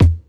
keys_29.wav